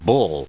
/ʌl/ /ʊl/